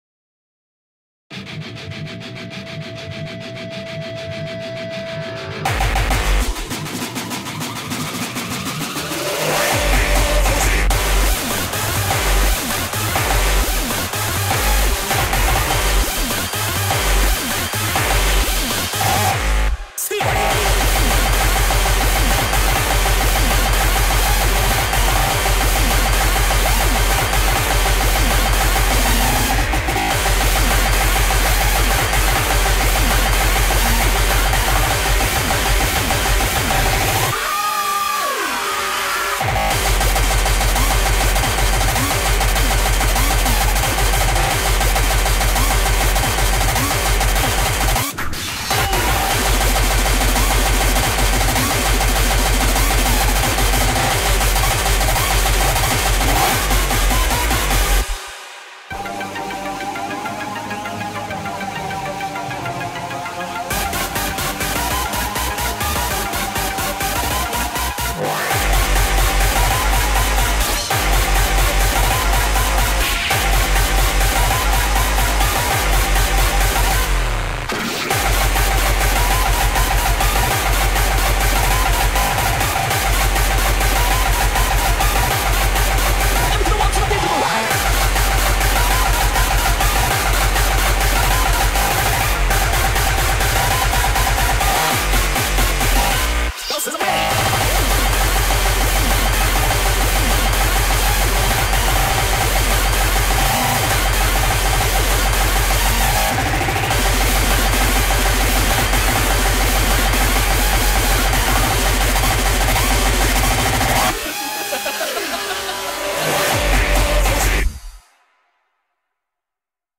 BPM400
Audio QualityCut From Video